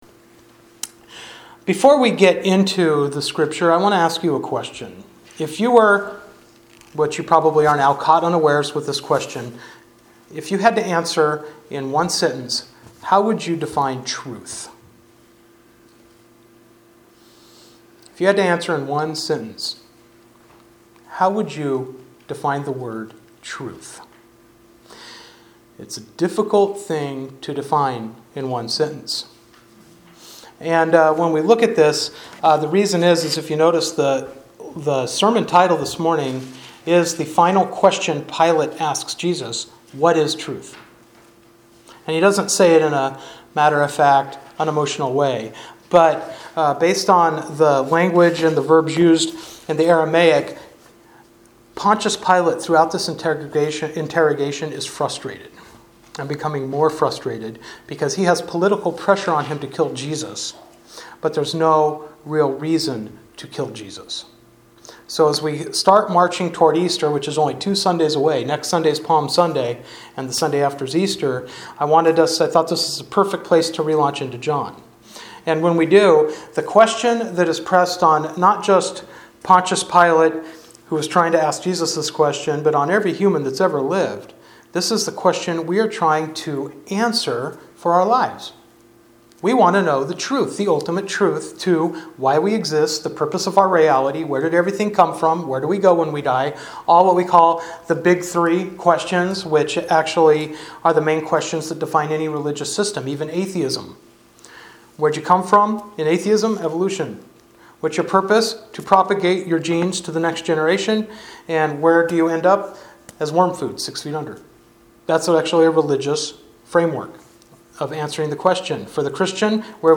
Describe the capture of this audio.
John 18:33-38 Service Type: Sunday Morning Worship Bible Text